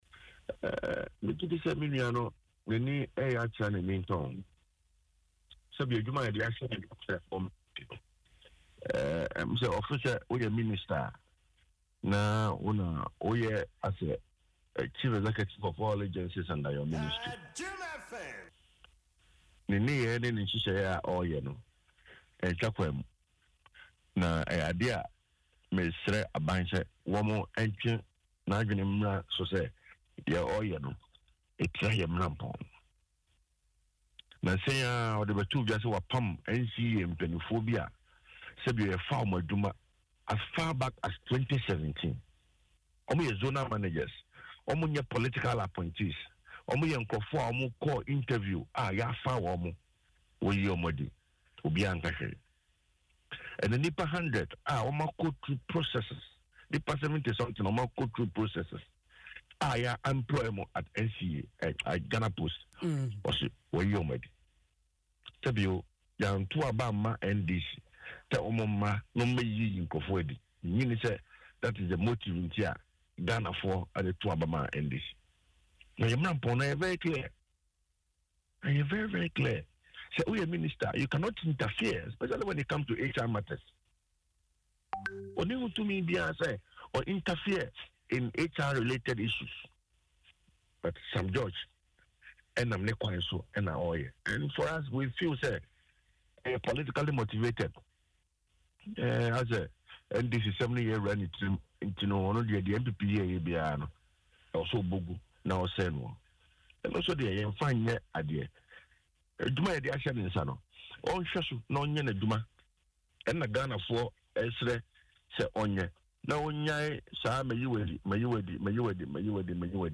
In an interview on Adom FM’s Dwaso Nsem, OPK admonished the minister to focus on his job rather than dismissing people.